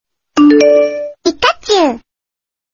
Som de notificação do Pikachu
Categoria: Toques
som-de-notificacao-do-pikachu-pt-www_tiengdong_com.mp3